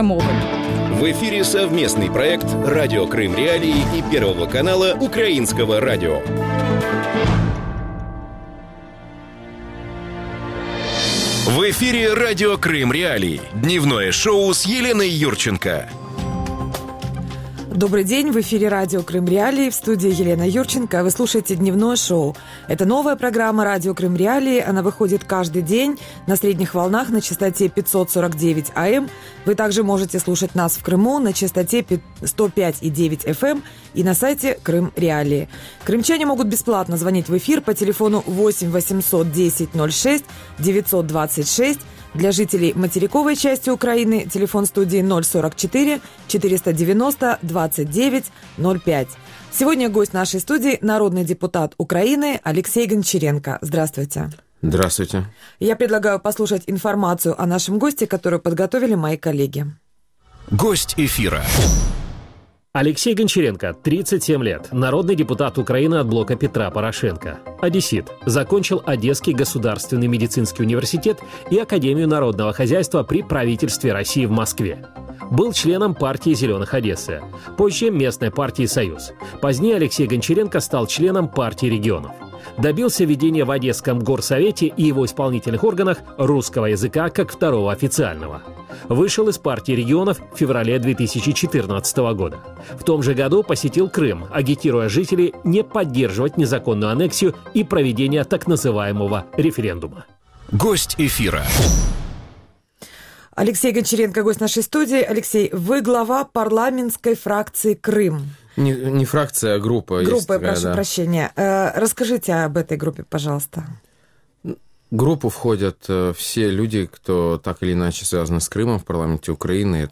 Крымская тема в Верховной Раде и ПАСЕ. Интервью с Алексеем Гончаренко
Гость студии – народный депутат Украины Алексей Гончаренко.